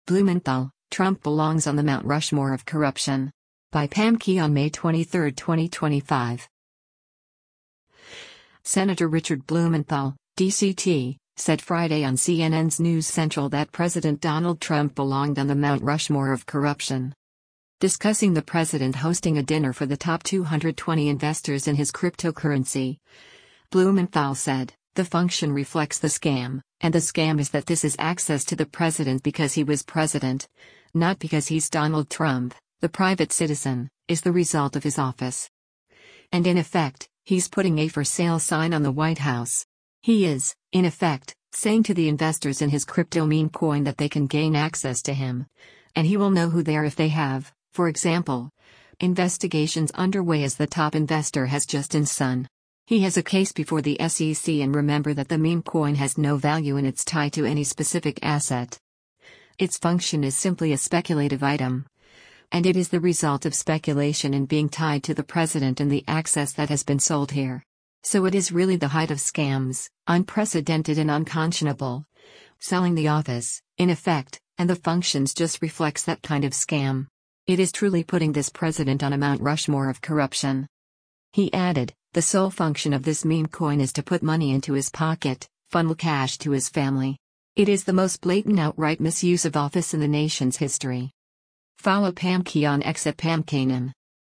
Senator Richard Blumenthal (D-CT) said Friday on CNN’s “News Central” that President Donald Trump belonged on the “Mount Rushmore of corruption.”